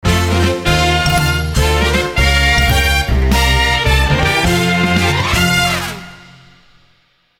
Game rip
Fair use music sample